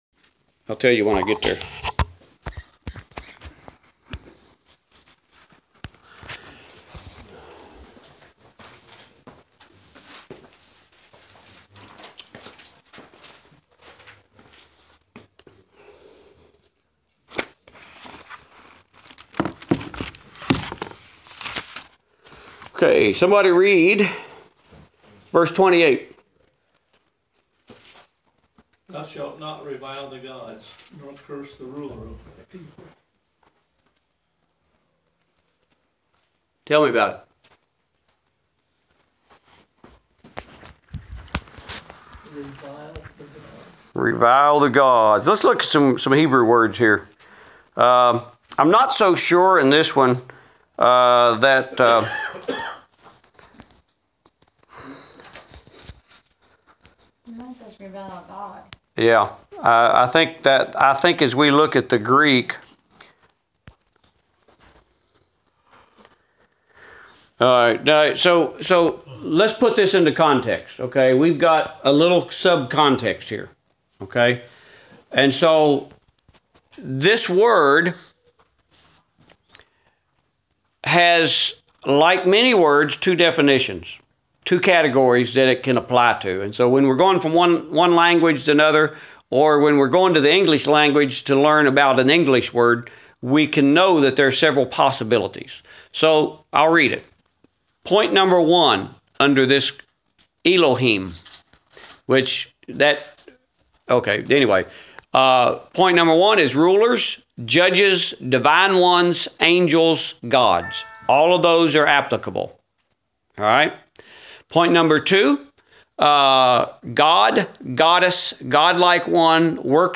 Adult Bible Class: Exodus